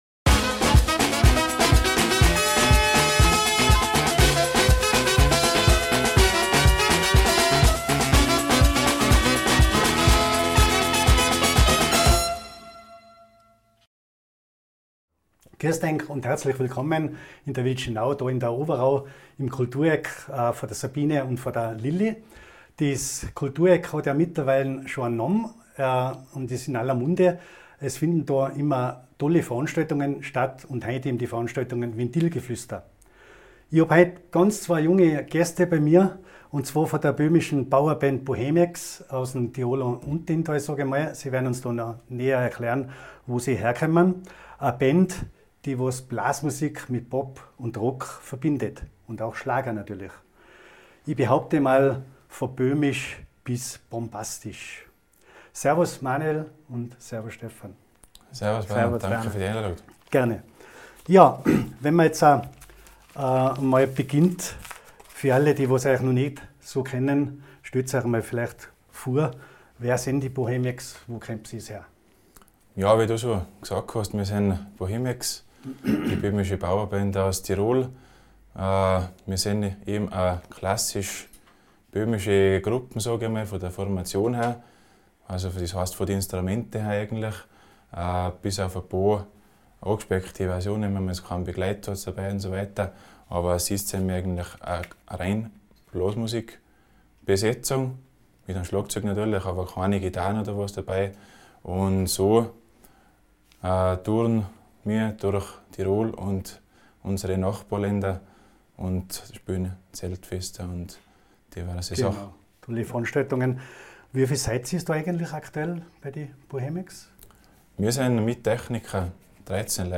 Die Bohemiacs stehen für echte Spielfreude und gelebte Blasmusik. Im Podcast erzählen die Musikanten, wie die Gruppe entstanden ist, was sie musikalisch verbindet und warum böhmisch-mährische Musik für sie weit mehr als nur ein Stil ist – nämlich Gefühl, Gemeinschaft und Tradition.